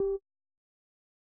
Sound / Effects / UI